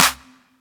Stay With Me Clap.wav